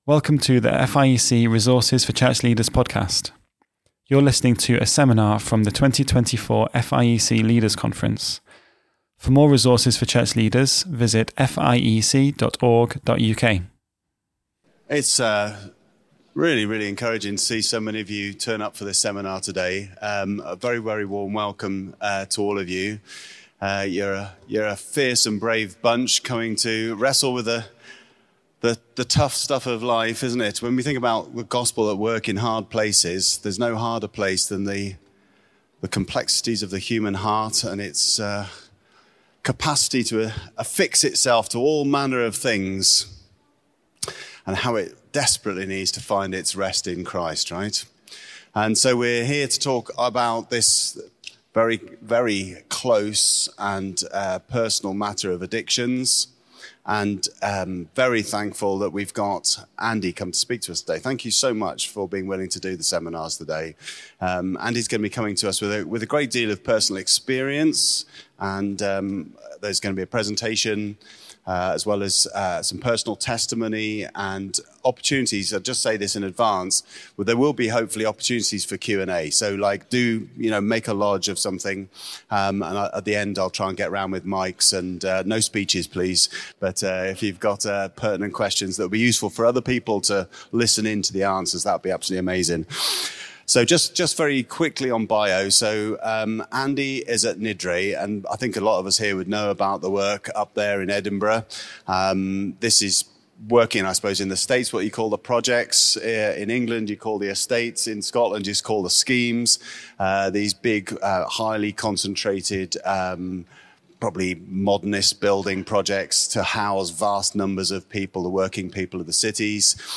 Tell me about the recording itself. Series: Leaders' Conference 2024